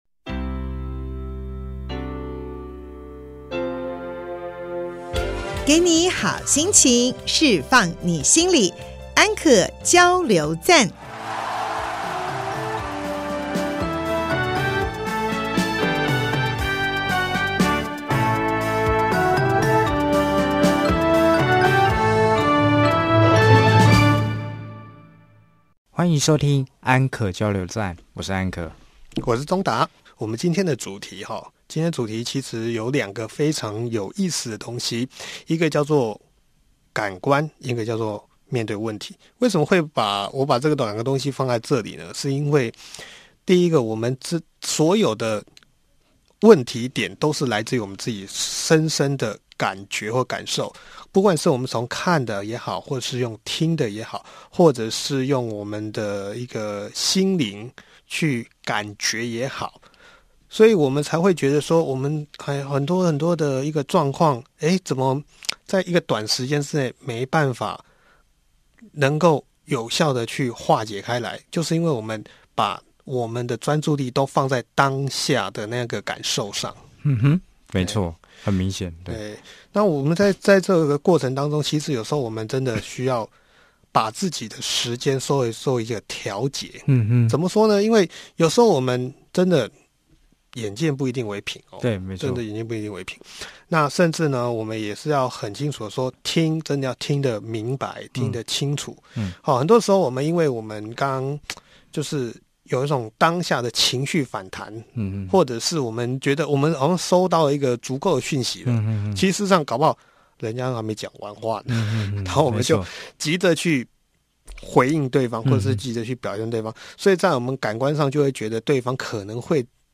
節目裡有生活點滴的分享、各界專業人物的心靈層面探析及人物專訪，比傳統心理節目多加了歷史人物與音樂知識穿插，更為生動有趣，陪伴您度過深夜時光，帶來一週飽滿的智慧與正能量。